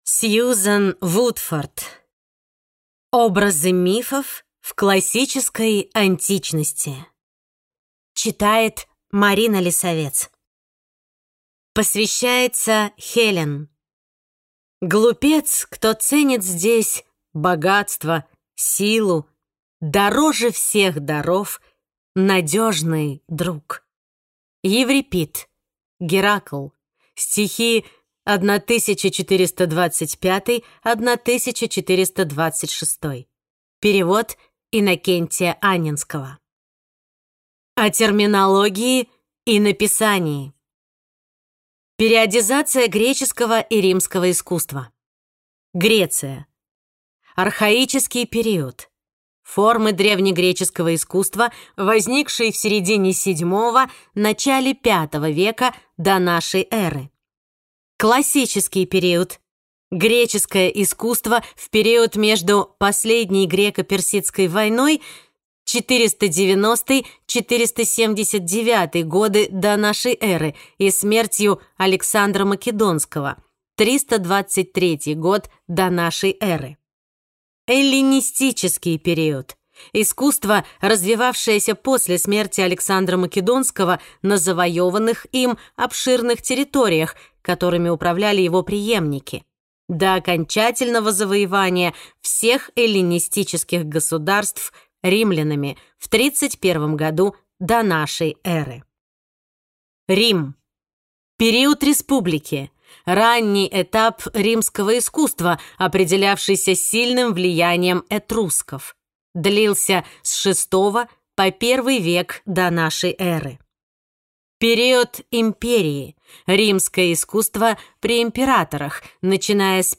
Аудиокнига Образы мифов в классической Античности | Библиотека аудиокниг